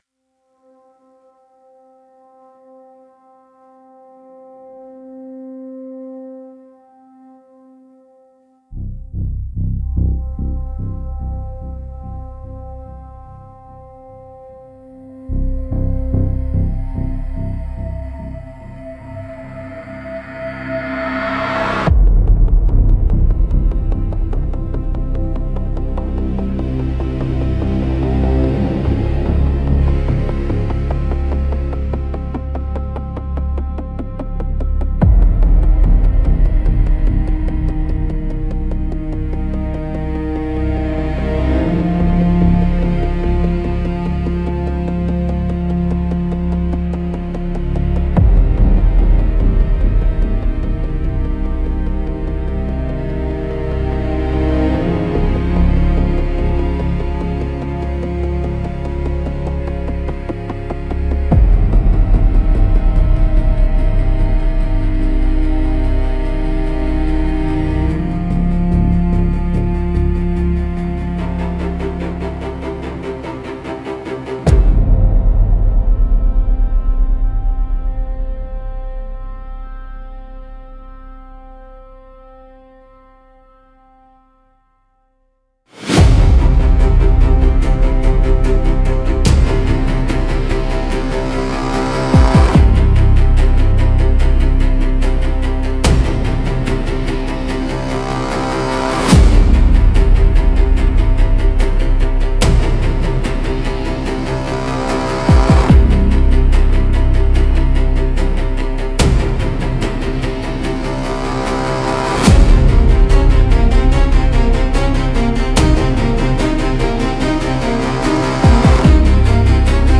Dark Minimal Suspense